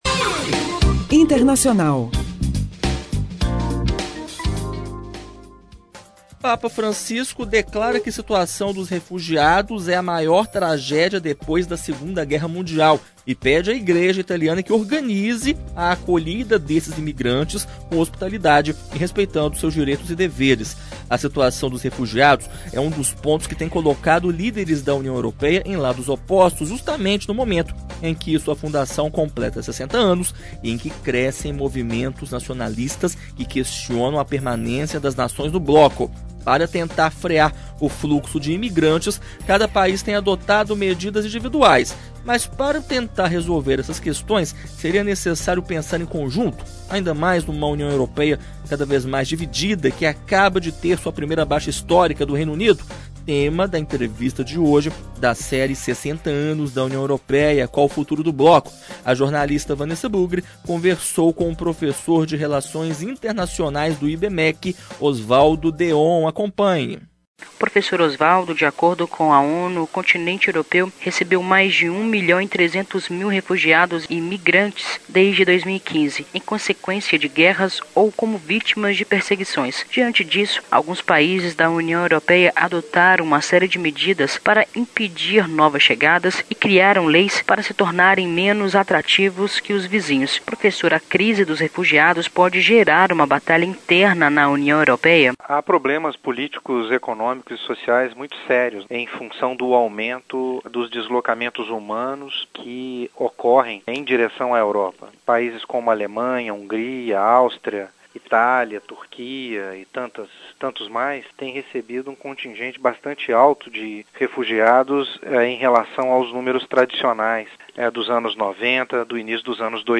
Especialistas avaliam o cenário internacional em cinco entrevistas veiculadas entre 20/3 e 24/3 no Jornal UFMG.